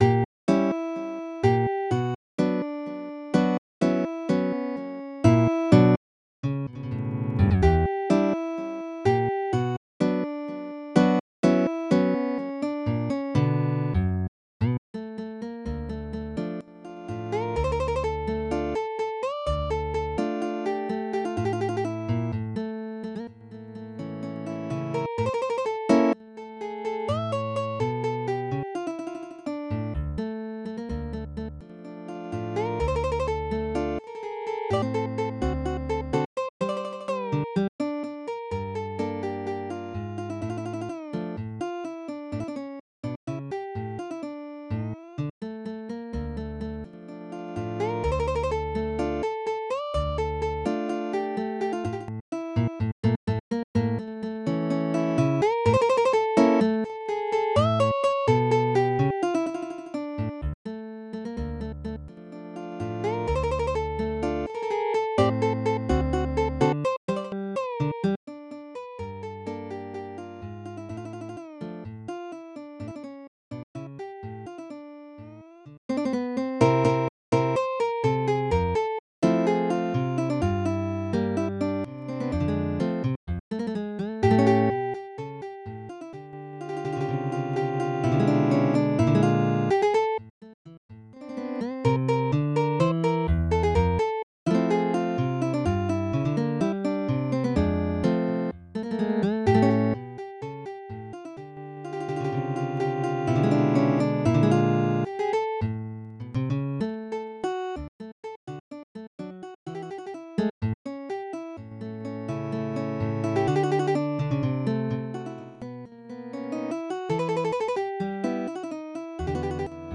タンゴ
Midi音楽が聴けます 4 280円